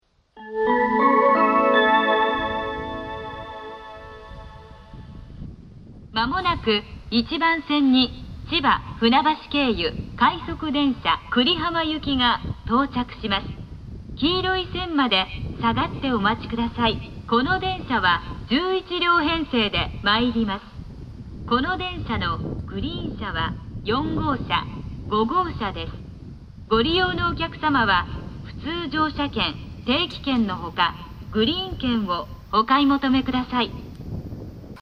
蘇我・千葉・東京方面   千葉支社標準放送
接近放送 千葉・船橋経由 快速電車 久里浜行き 11両編成の接近放送です。
非密着収録のため、雑音が多いです。